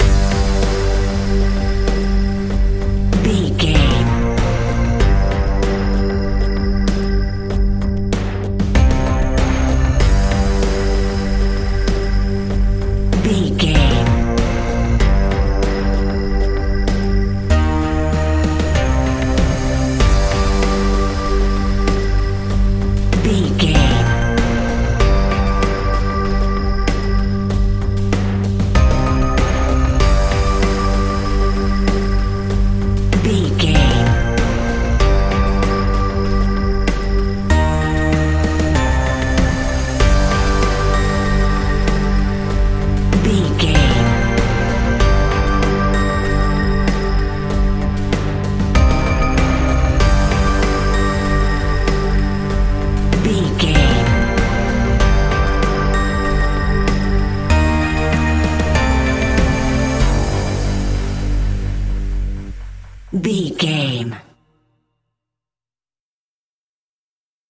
Aeolian/Minor
scary
tension
ominous
dark
haunting
eerie
synthesiser
ticking
electronic music
electronic instrumentals